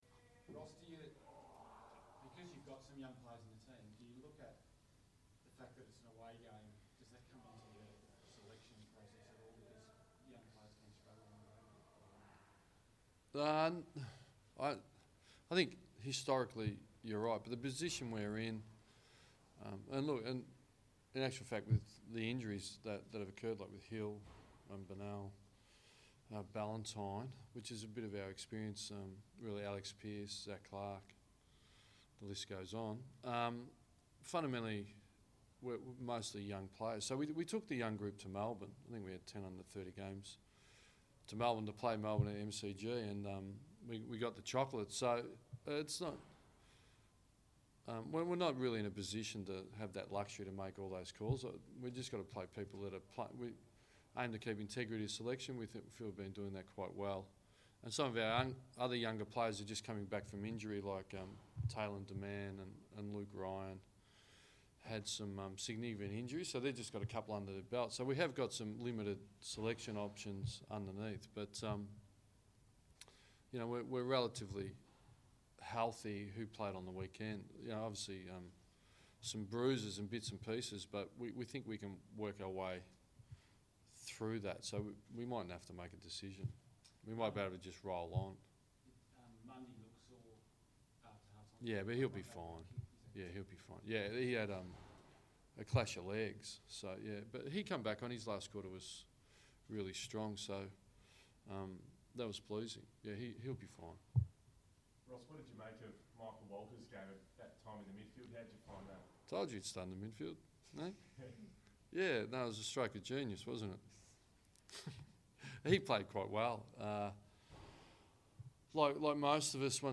Ross Lyon press conference - 10 May
Ross Lyon chats to the media ahead of Freo's clash against the Tigers.